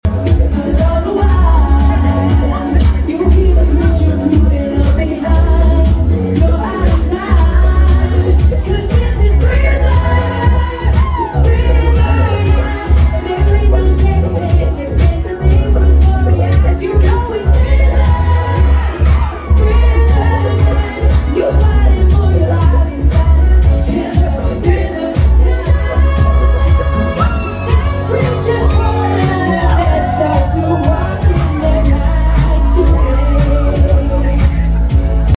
The crowd brought the energy last night at Buzzards and Bees goth prom at Velour!